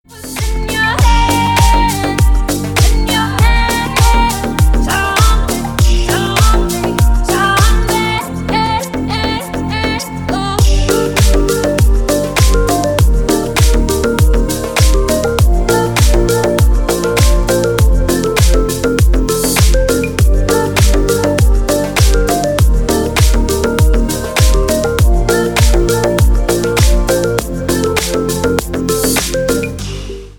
• Песня: Рингтон, нарезка